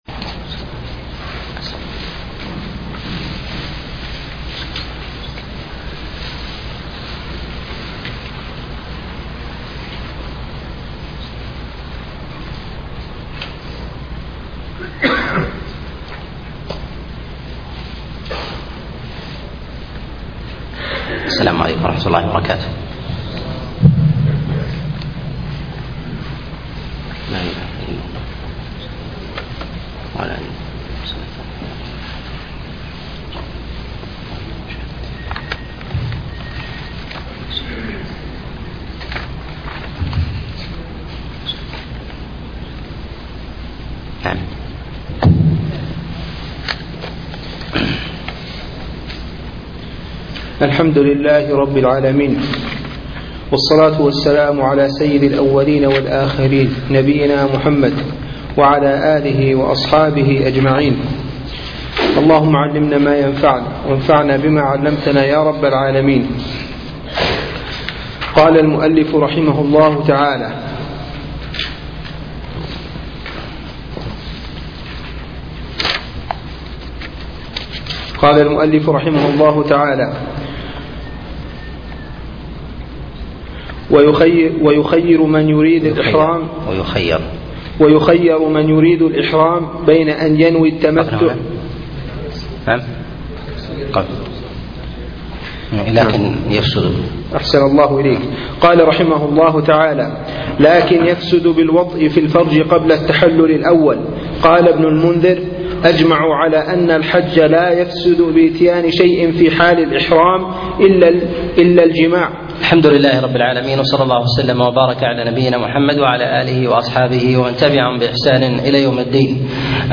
شرح كتاب الحج من منار السبيل الدرس 2